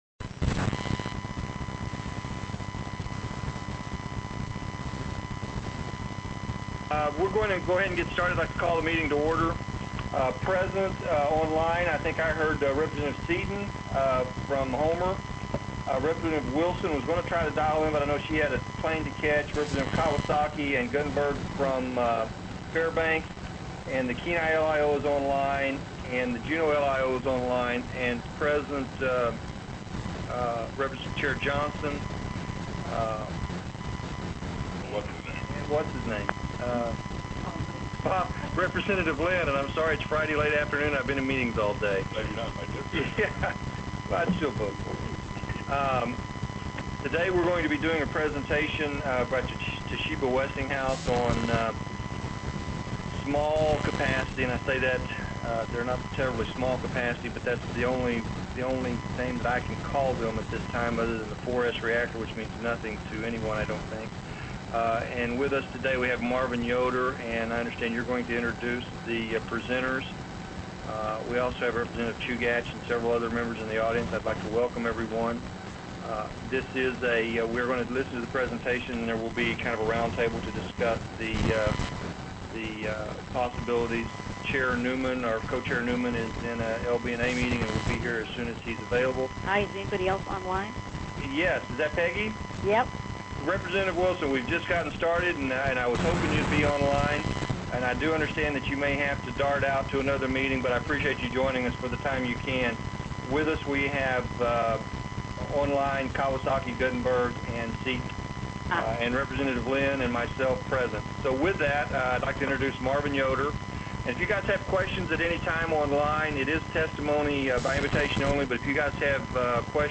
Presentation and Roundtable Discussion TELECONFERENCED on Toshiba/Westinghouse 4-S, 10MWe Design Unit for Energy Production in Alaska